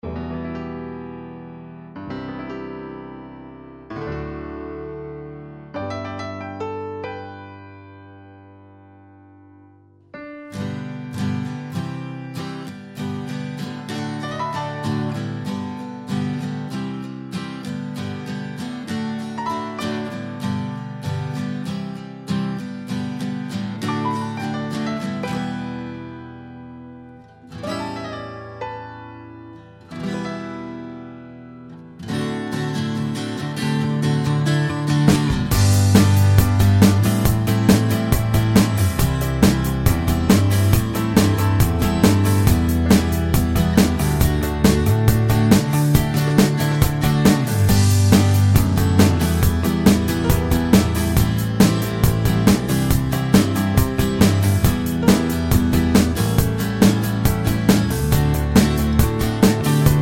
With New Intro Chord Pop (1970s) 4:24 Buy £1.50